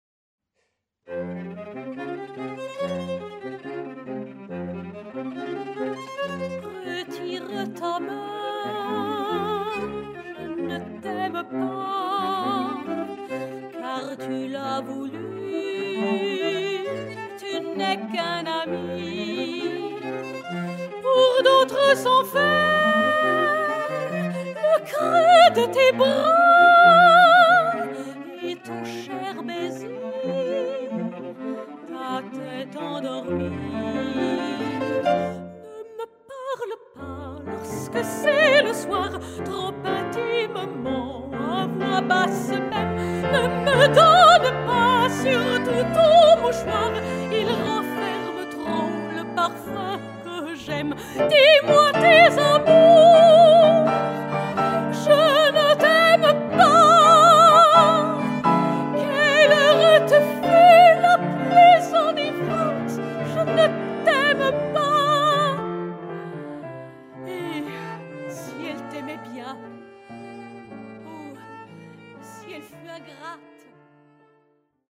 chant (soprano)
saxophone(s)
piano, chant, arrangement
alto, accordéon,arrangement